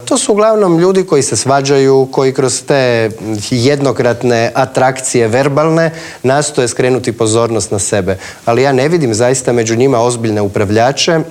ZAGREB - Premijer, ministri, šefovi oporbenih stranaka, gradonačelnici - svi oni bili su gosti Intervjua tjedna Media servisa.
Oporba nije uspjela svrgnuti HDZ s trona, a predsjednik Hrvatskog sabora Gordan Jandroković ugostio nas je u svojim odajama te političkim oponentima poručio: